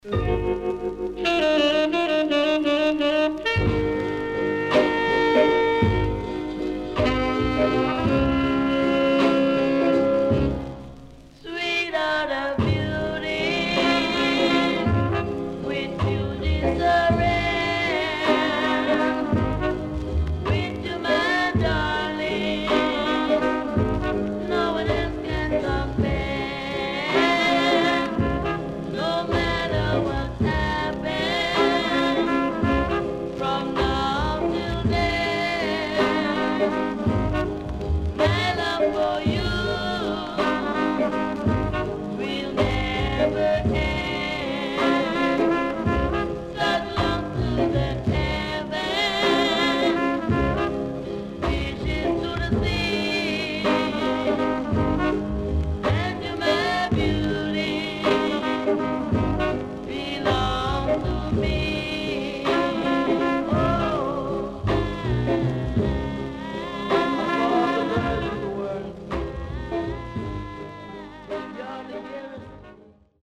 HOME > SKA
SIDE A:少しチリノイズ、プチノイズ入ります。